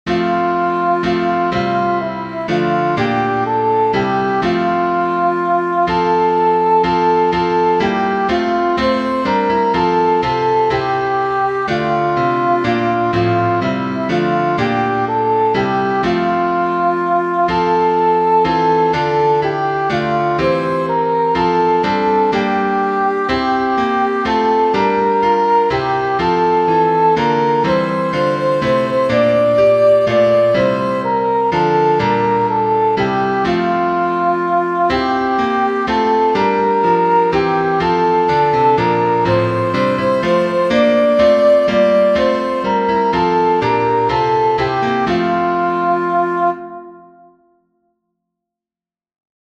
Soprano
“Holy God, We Praise Thy Name” (original German: “Großer Gott, wir loben dich”) is an ecumenical hymn.
grosser_gott_holy_god_we_praise-soprano.mp3